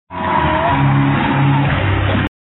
Download Crowd Cheer sound effect for free.
Crowd Cheer